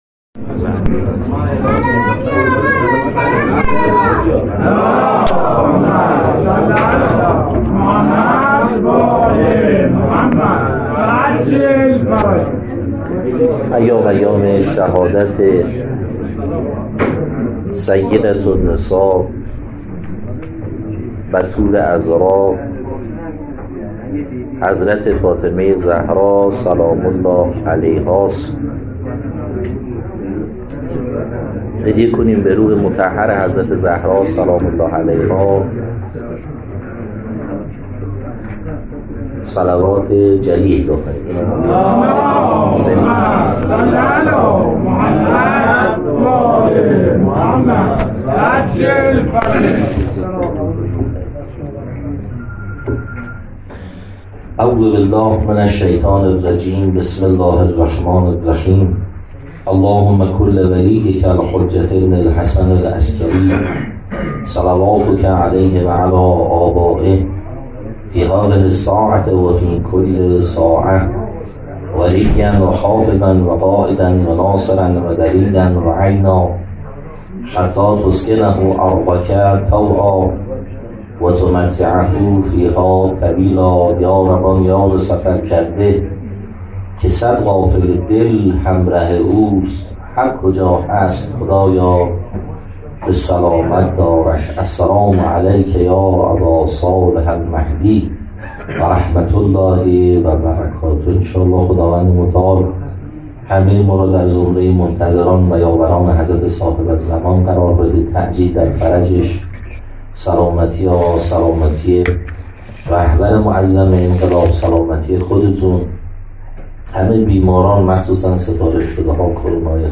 هییات رزمندگان اسلام شهرری